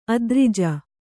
♪ adrija